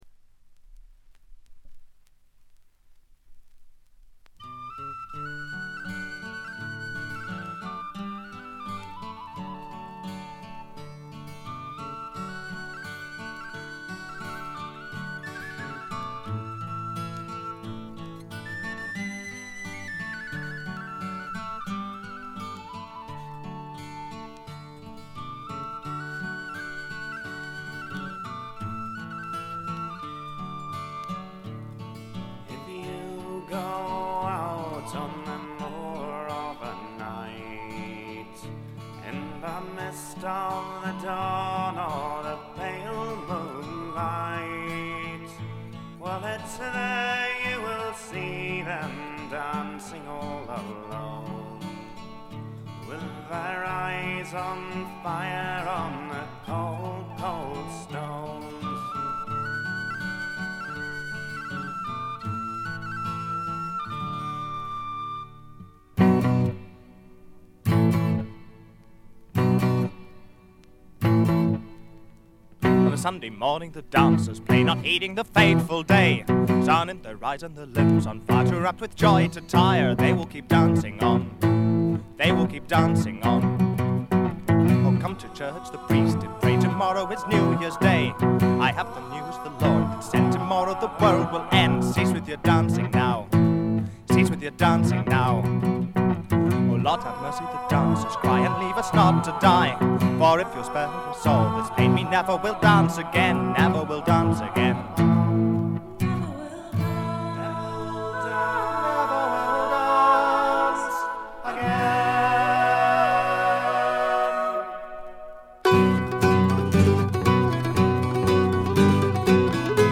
スコットランドはエディンバラの4人組トラッド・フォーク・グループ。
試聴曲は現品からの取り込み音源です。